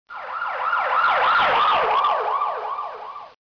Ambulans siren, Sirens, Android